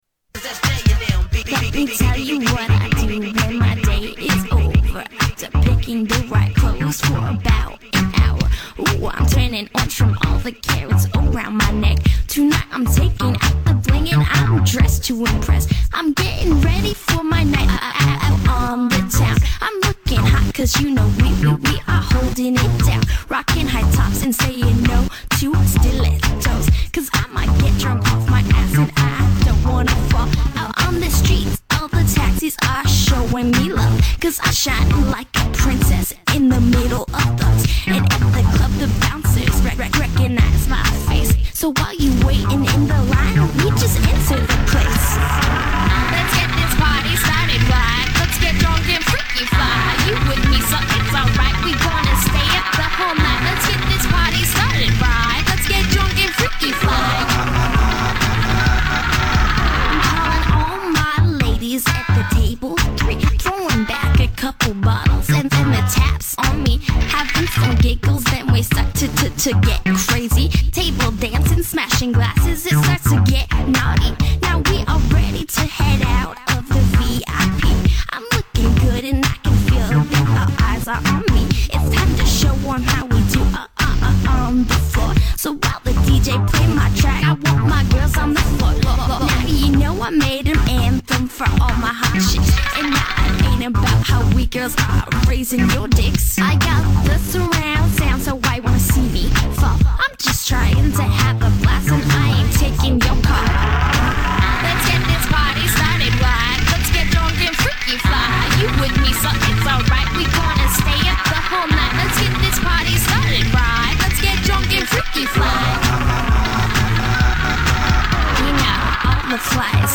Electro Rap